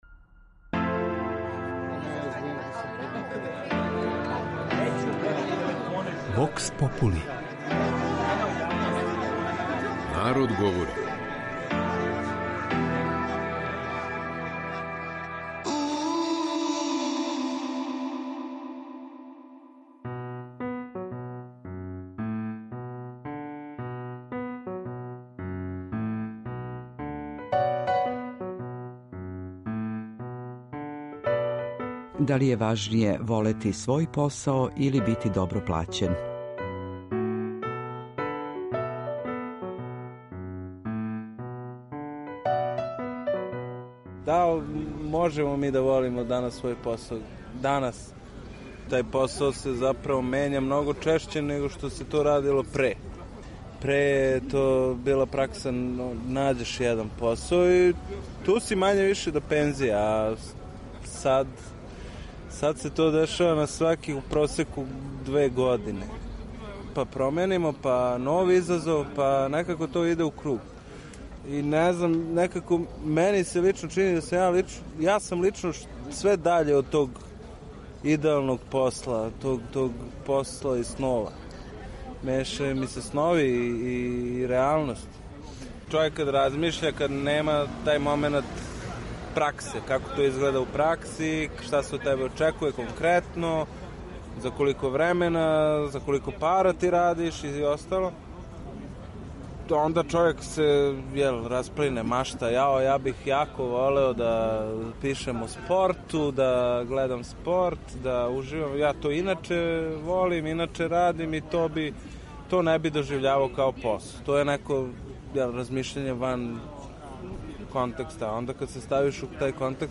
У данашњој емисији, питали смо наше суграђане: „Да ли је важније волети свој посао или добро зарађивати?"
Вокс попули